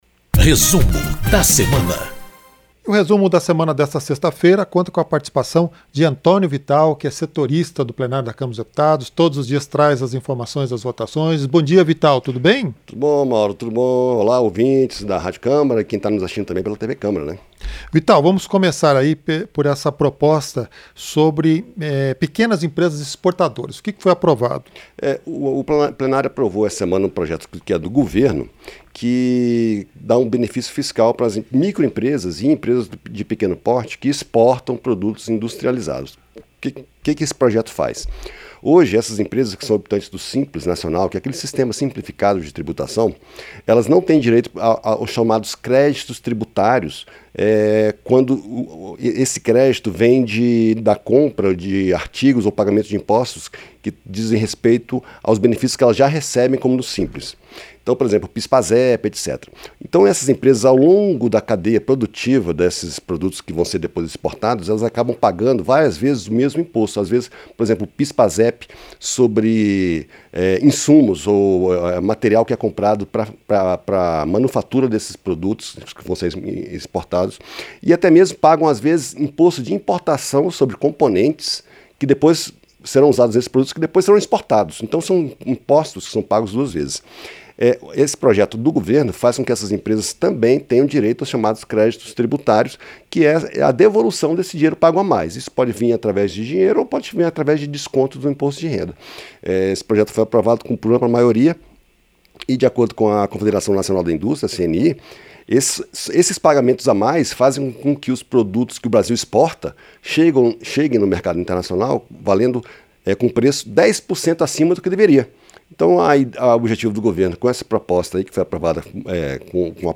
Um resumo dos principais fatos da semana nas comissões e no plenário da Câmara dos Deputados